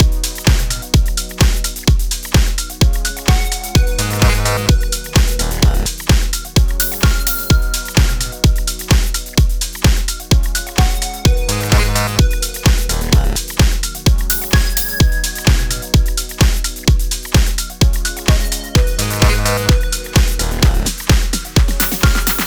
Chill
Gb Major
Robotic Bass